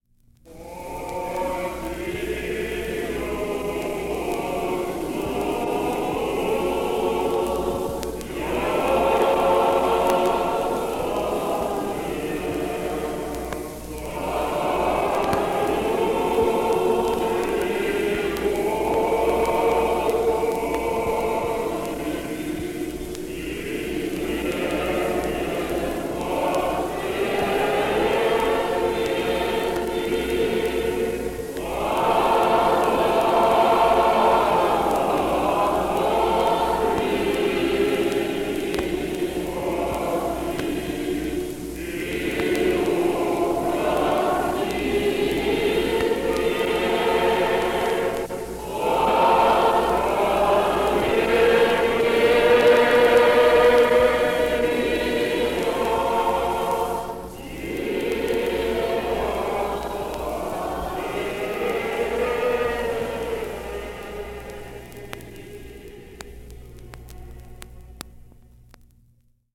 Служение Патриарха Алексия I-го. Великий Пост и Пасха 1962г.